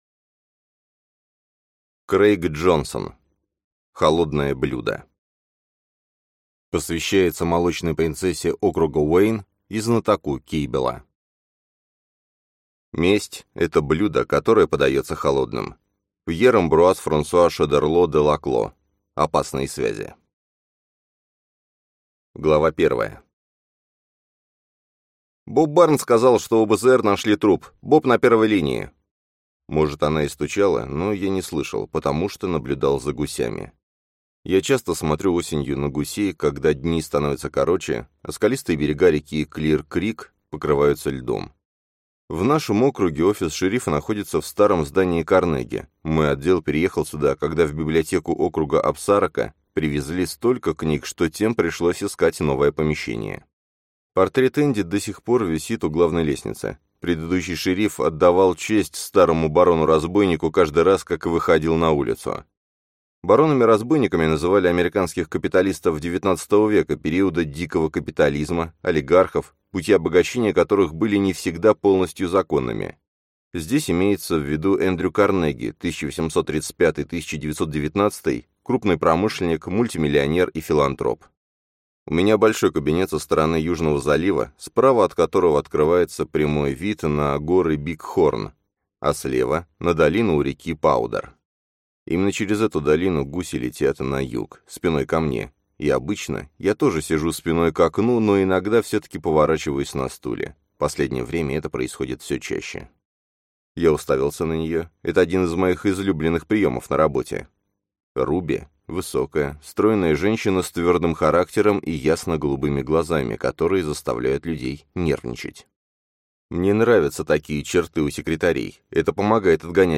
Аудиокнига Холодное блюдо | Библиотека аудиокниг